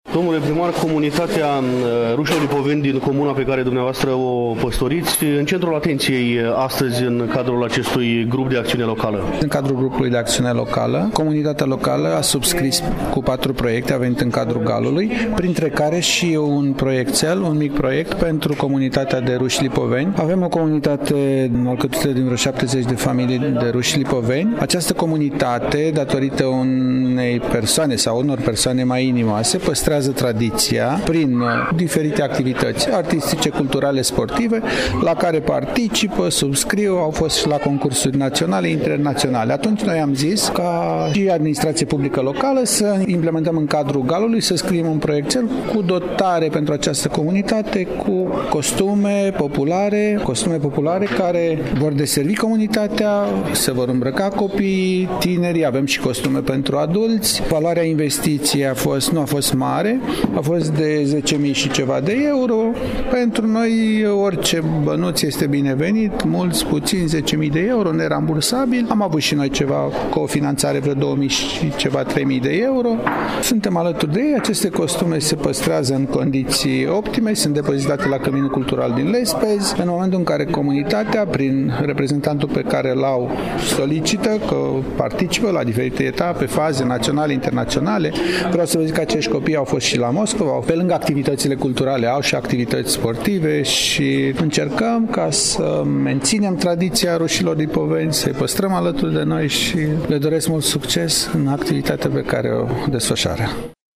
Evenimentul a avut loc în incinta Hanului Andri Popa din Comuna Valea Seacă, Iași, în ziua de vineri, 6 septembrie, începând cu ora 10.
Imediat după încheierea conferinței, l-am provocat, la un scurt dialog, pe domnul Ioan Lazăr, primar al Comunei Lespezi, cel care ne vorbește despre proiectul depus în cadrul GAL-ului, proiect care prevede – dotări pentru comunitatea staroveră din comuna pe care o administrează.